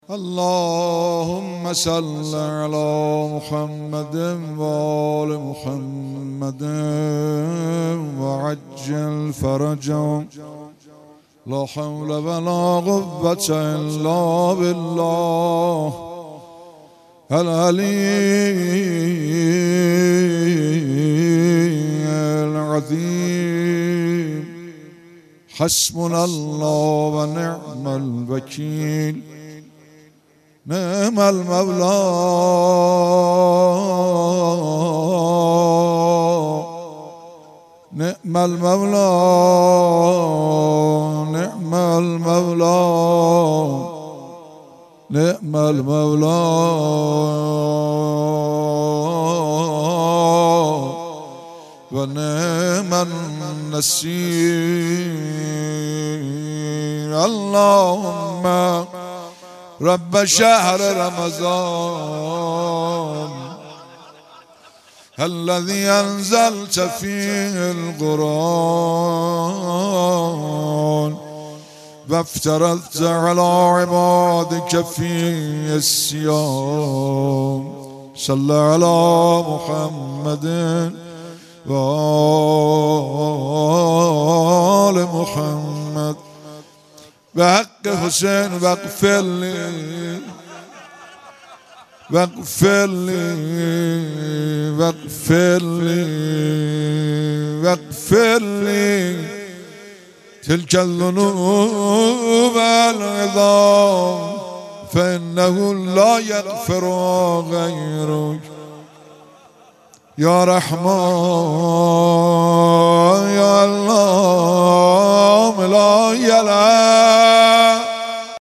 شب چهارم ماه مبارک رمضان در حرم حضرت معصومه سلام الله علیها